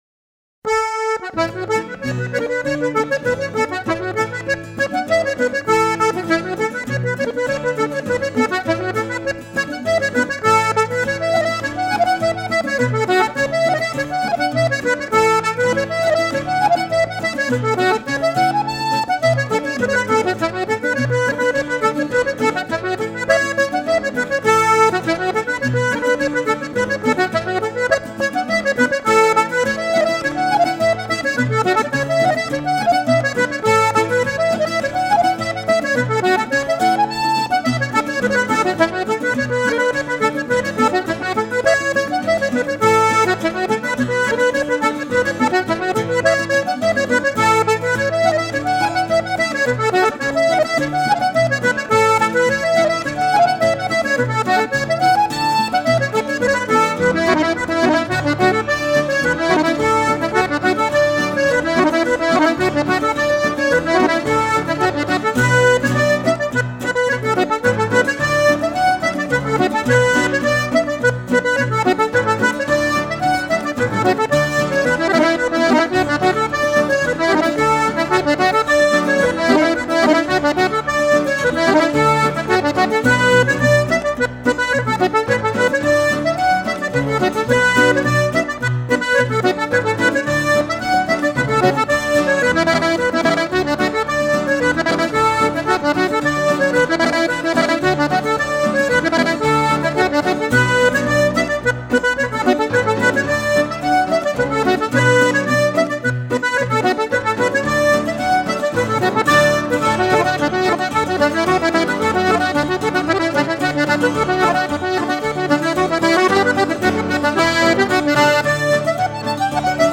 Reel two hands
Two hands reel.mp3